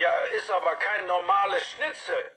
Werbung Prosodie: accent tonique und intonation descendante en fin de phrases déclaratives